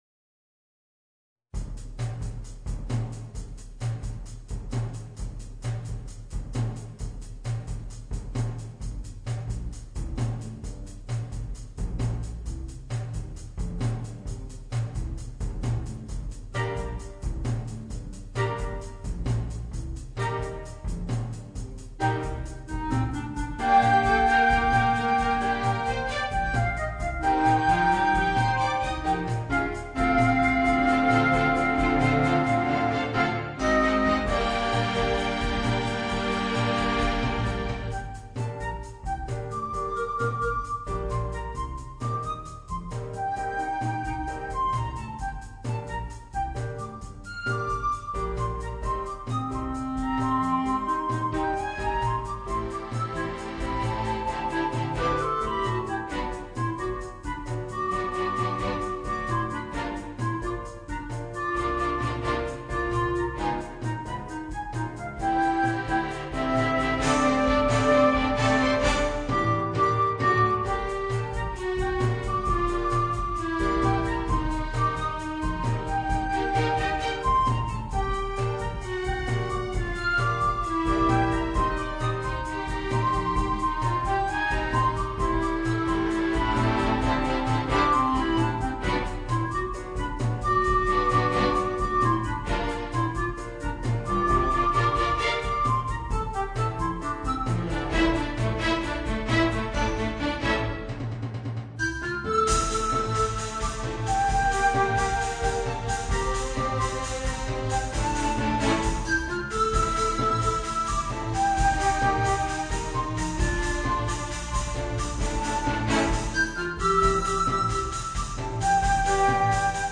Flute, Clarinet and Rhythm Section and Strings